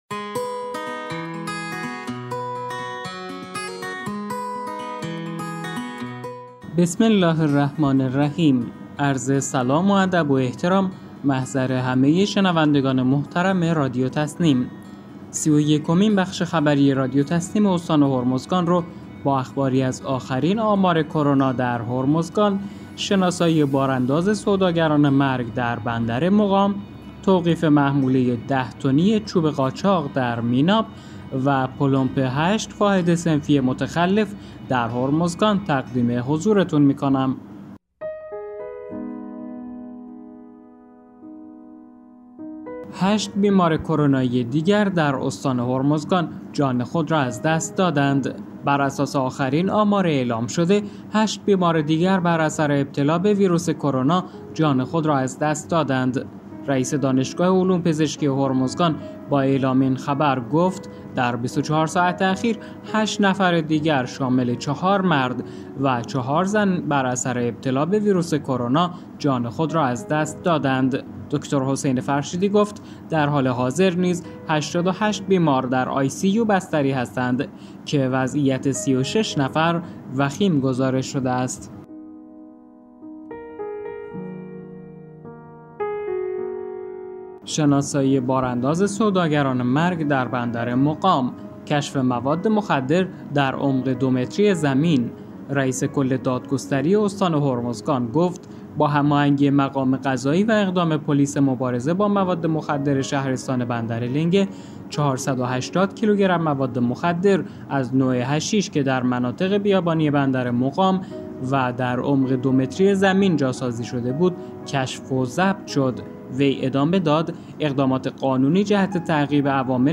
به گزارش خبرگزاری تسنیم از بندرعباس، سی‌ و یکمین بخش خبری رادیو تسنیم استان هرمزگان با اخباری از آخرین آمار کرونا در هرمزگان، شناسایی بار انداز سوداگران مرگ در بندر مقام، توقیف محموله 10 تنی چوب قاچاق در میناب و پلمپ 8 واحد صنفی متخلف در هرمزگان منتشر شد.